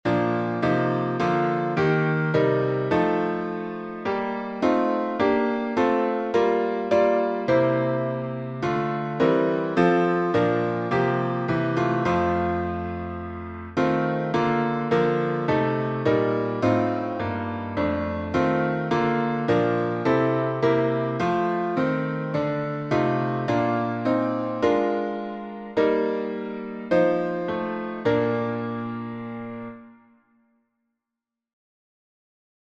Key signature: B flat major (2 flats) Time signature: 4/4